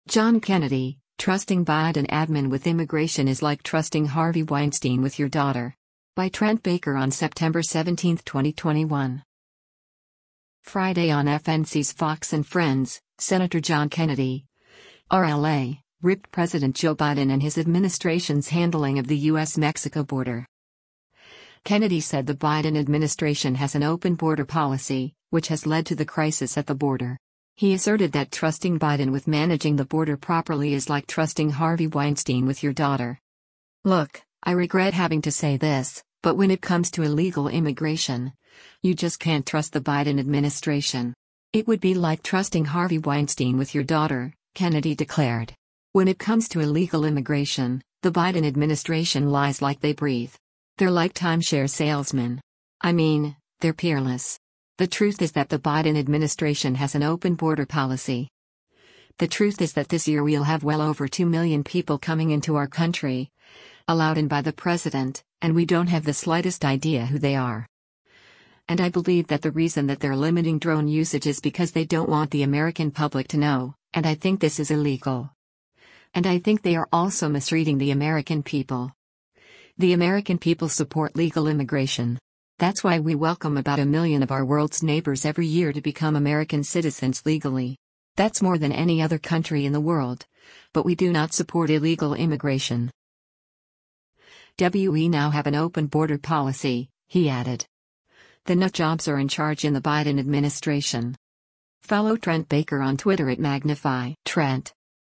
Friday on FNC’s “Fox & Friends,” Sen. John Kennedy (R-LA) ripped President Joe Biden and his administration’s handling of the U.S.-Mexico border.